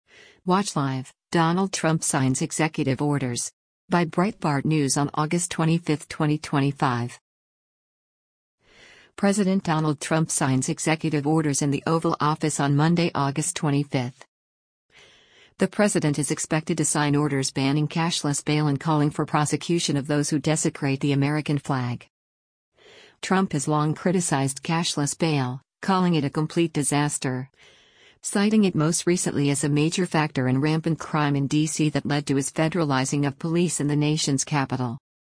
President Donald Trump signs executive orders in the Oval Office on Monday, August 25.